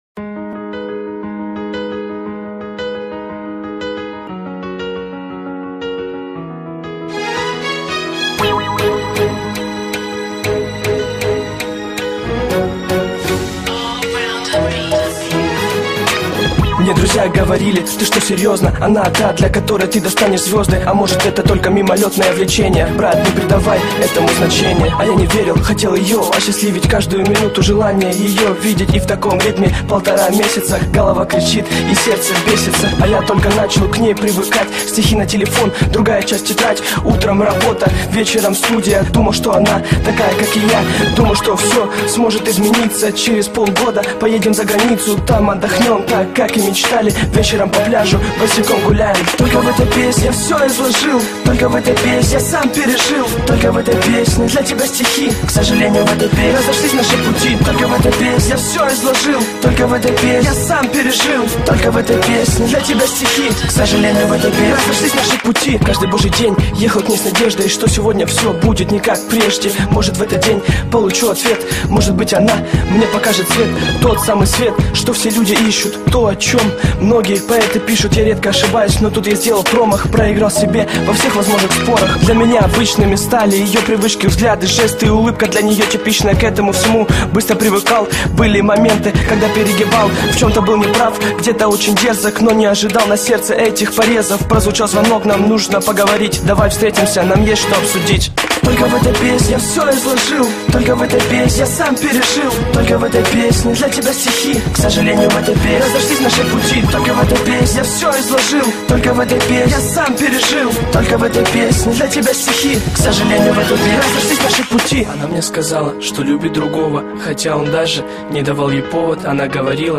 rep_pro_lyubov__moy_lyubimyy_rep.mp3